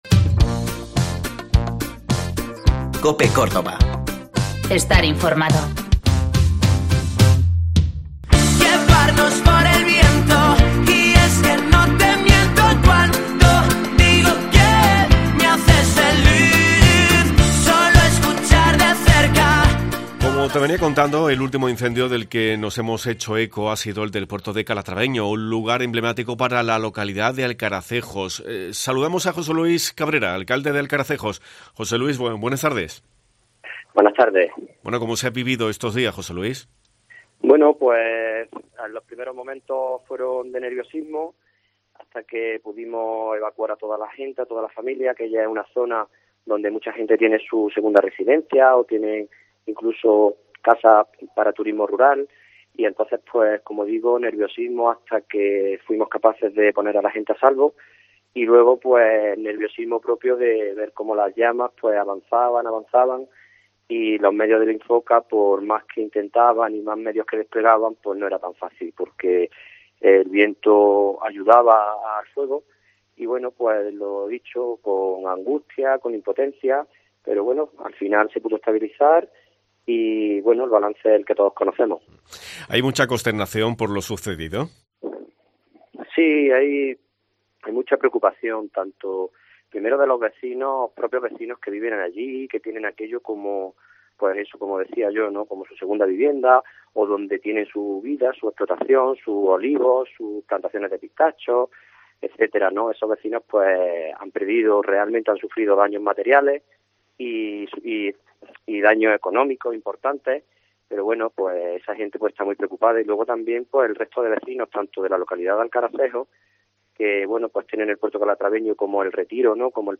José Luis Cabrera, alcalde de Alcaracejos